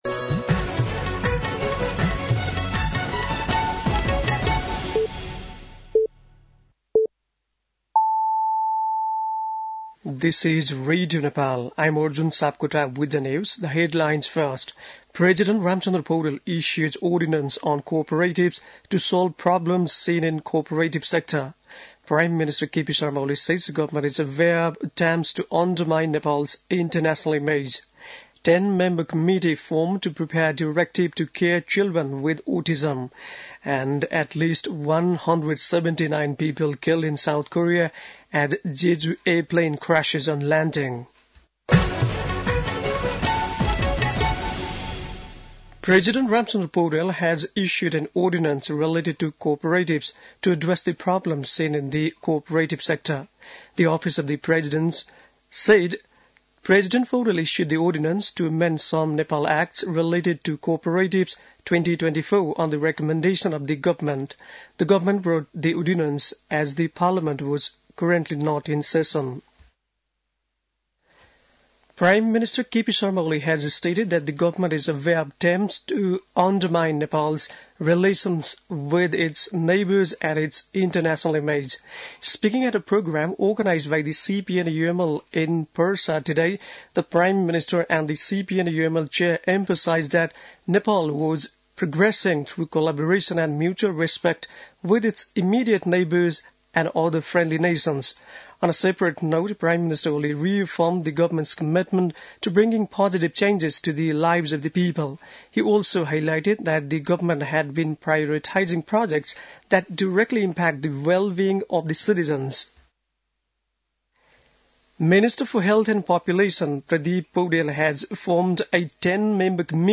बेलुकी ८ बजेको अङ्ग्रेजी समाचार : १५ पुष , २०८१
8-pm-english-news-9-14.mp3